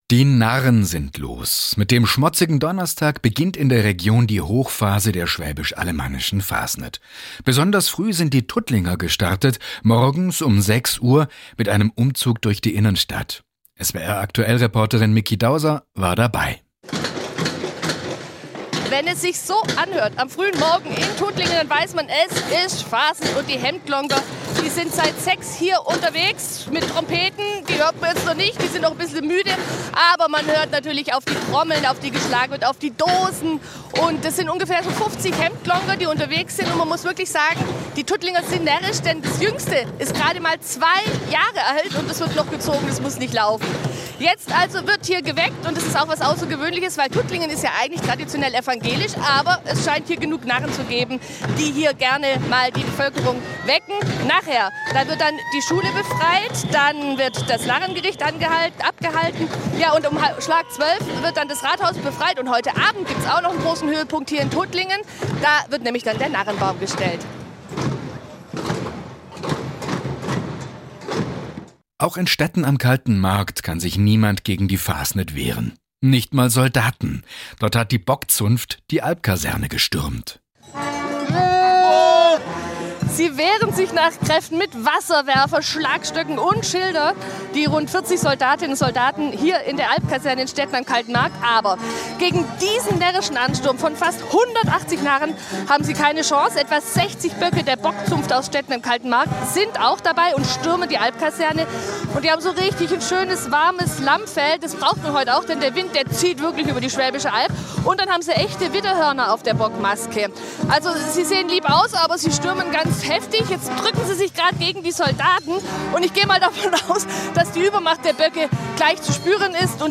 Das war der Schmotzige Donnerstag von Tuttlingen bis Tübingen
Die Stadt mit Schellen wecken, das Rathaus stürmen und Schüler befreien: In Tuttlingen, Stetten am Kalten Markt, Schörzingen, Spaichingen und Bühl ist es am Schmotzige närrisch zugegangen.